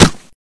shell_impact2.ogg